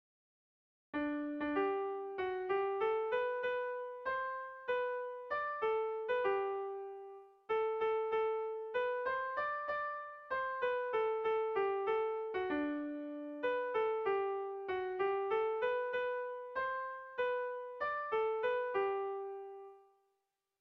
Erritmo interesgarria du.
Seiko txikia (hg) / Hiru puntuko txikia (ip)
ABA